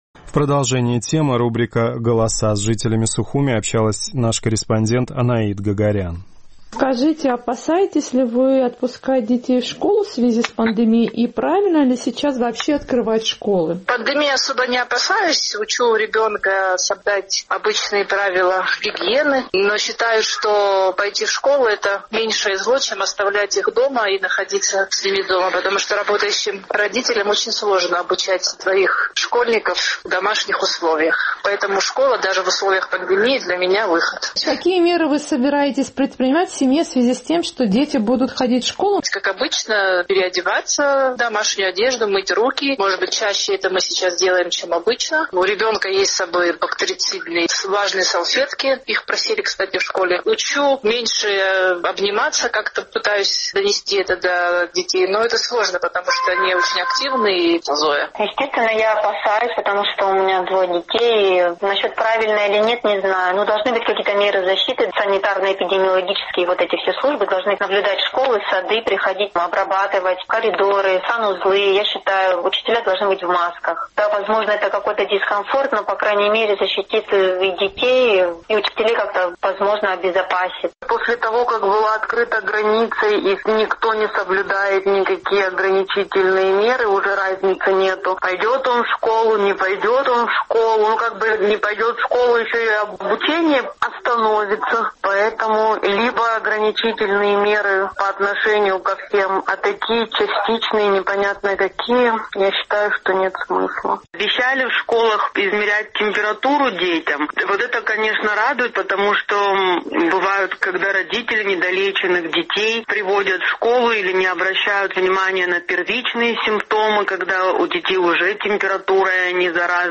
Учеба нужна и важна, но многие выражают сомнение, что школы Абхазии полностью готовы к учебному году в условиях пандемии. Наш традиционный сухумский опрос.